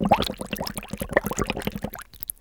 Index of /90_sSampleCDs/E-MU Producer Series Vol. 3 – Hollywood Sound Effects/Water/Bubbling&Streams